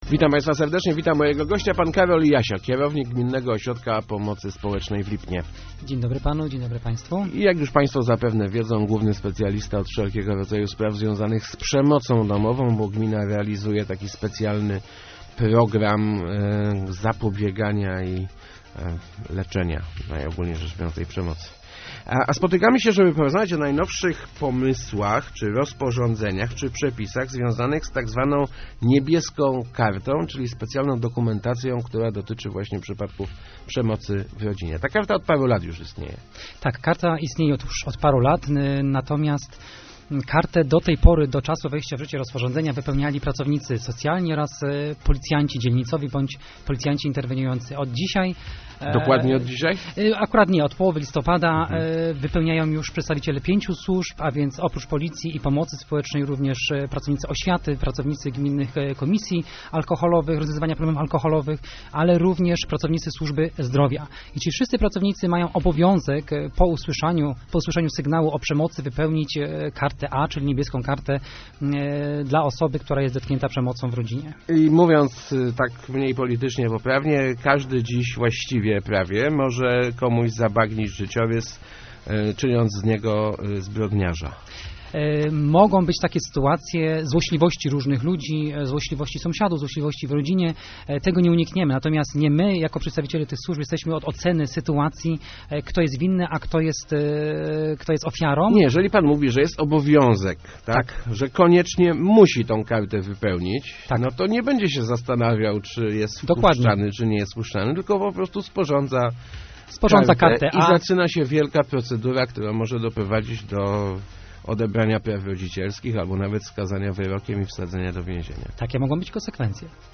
Nowe przepisy dotyczące "Niebieskiej Karty" nakładają obowiązek informania o przemocy w rodzinie na większą grupę osób - mówił w Rozmowach Elki